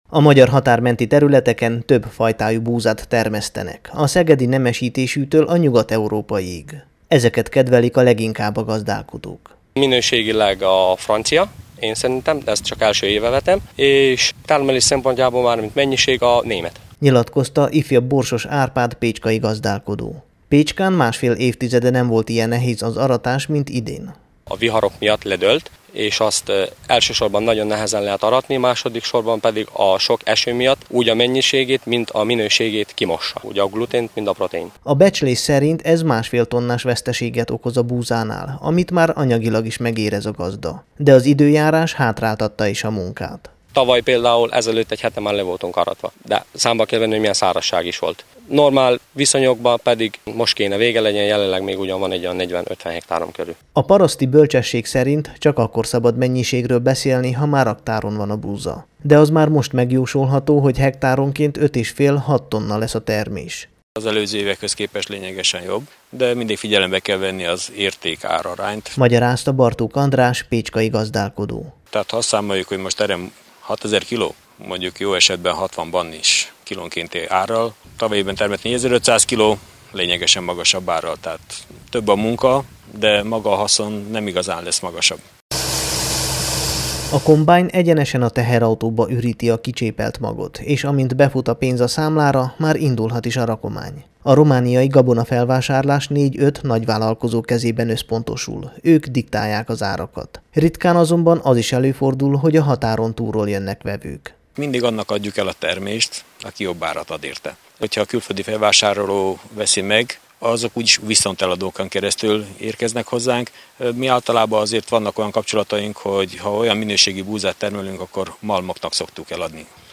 az Arad megyei Pécskán készített búzaaratási körképet a Temesvári Rádió számára